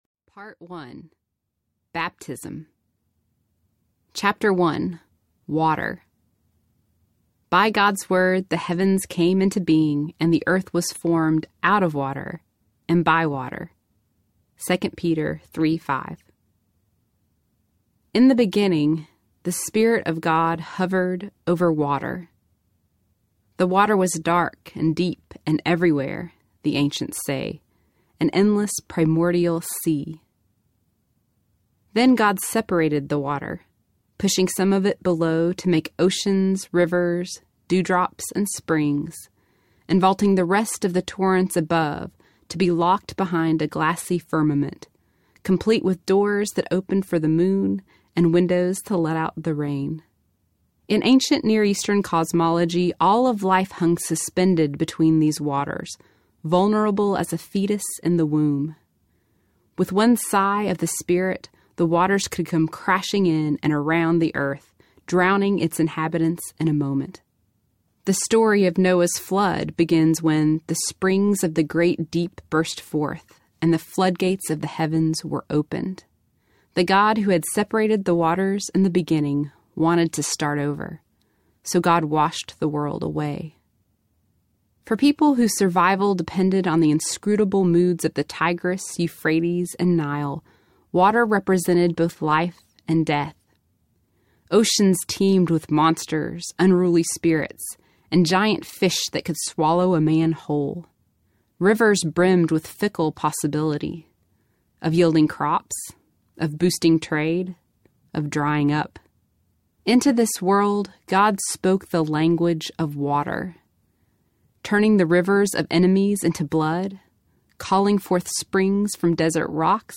Searching For Sunday Audiobook
Narrator